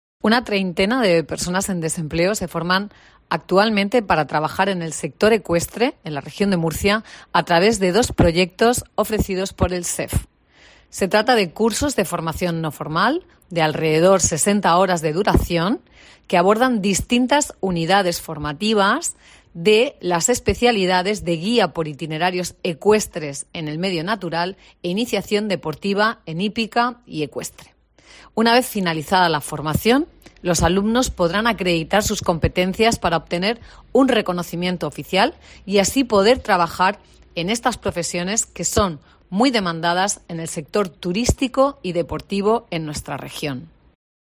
Marisa López, directora general del SEF